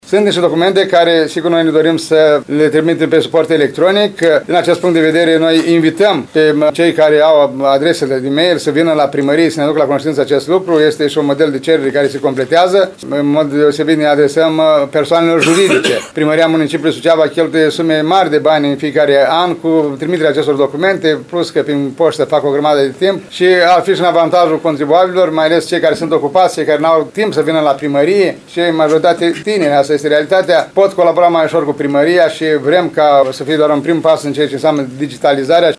Primarul ION LUNGU a declarat că firmele “trebuie să adopte tehnologii moderne” în relația cu municipalitatea, în acest fel creându-se legături facile.